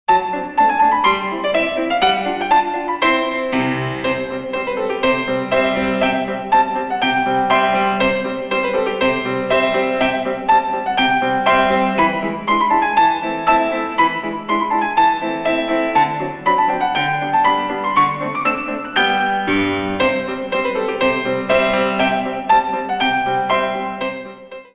64 Counts